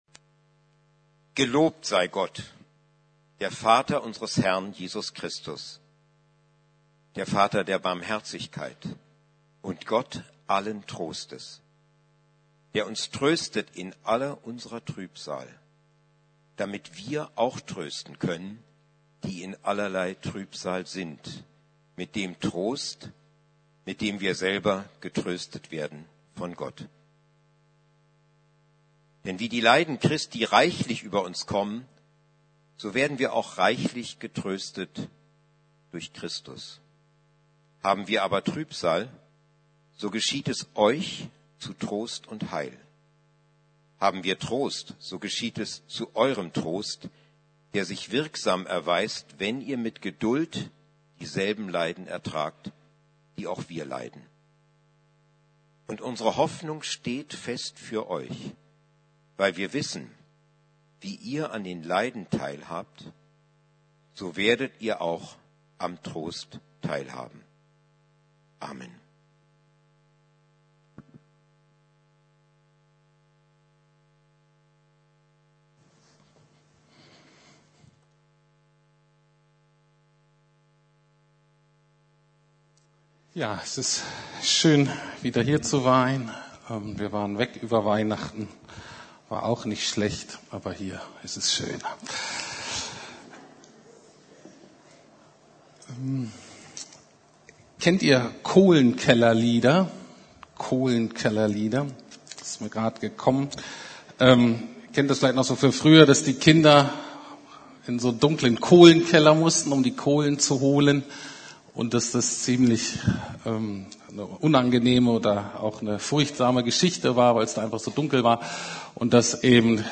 Trost - Jahreslostung 2016 - Einführung - Teil 1 ~ Predigten der LUKAS GEMEINDE Podcast